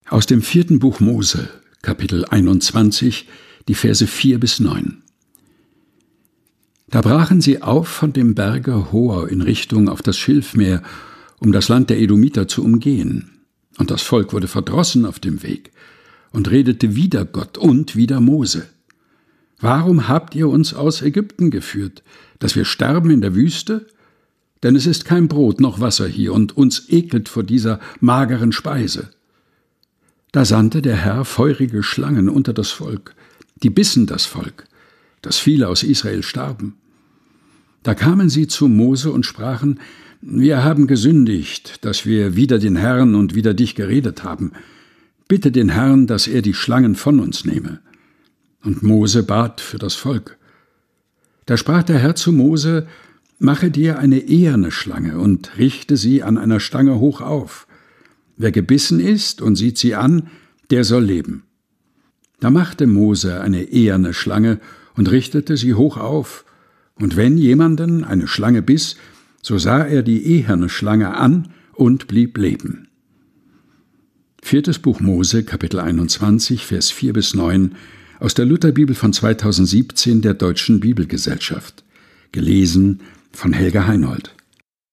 Predigttext zum Sonntag Reminiscere des Jahres 2024.